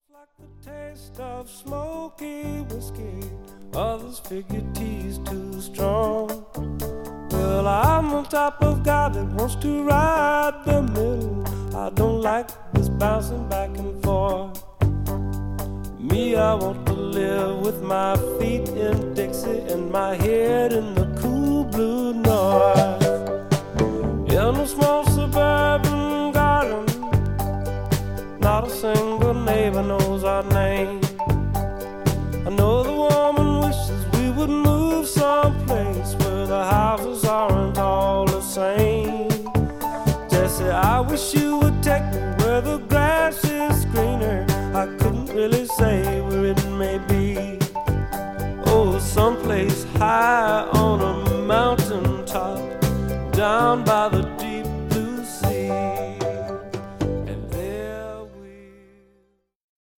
カントリー、フォーク、ジャズ、テックス・メックスなどを背景にした温かみのある内容です。